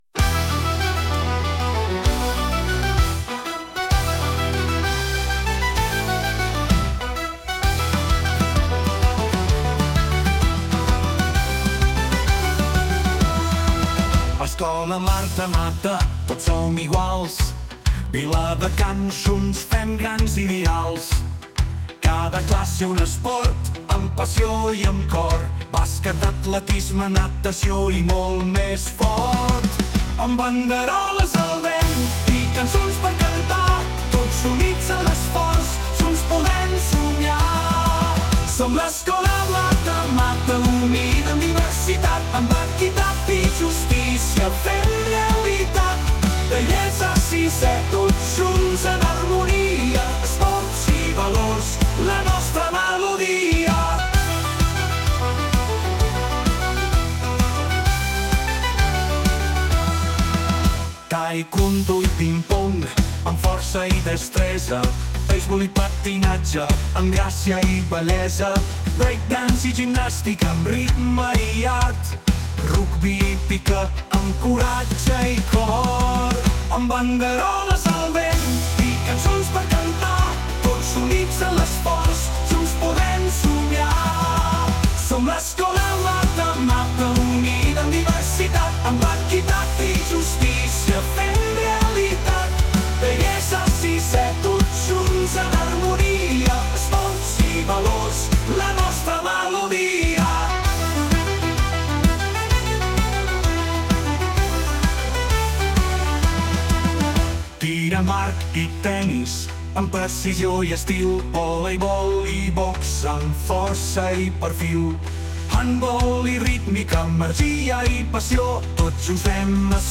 Himne-escola-Marta-Mata-1.mp3